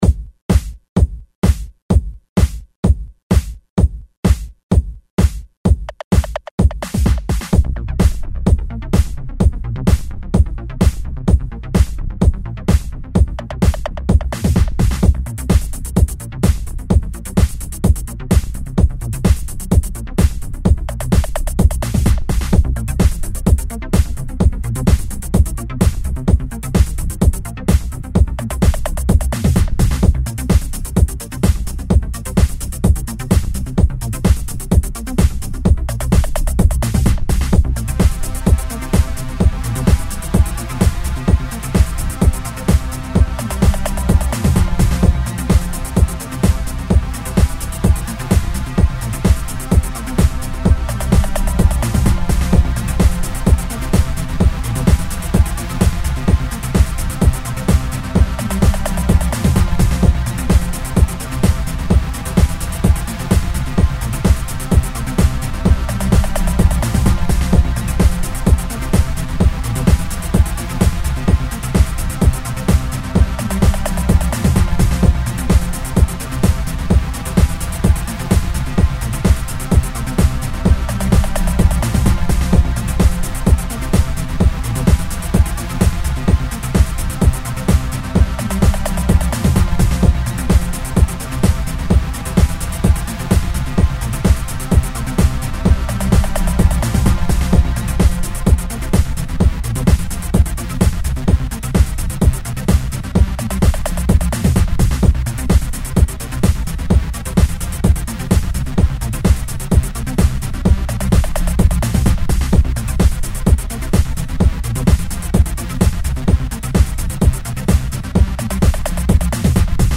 Trance - 2:47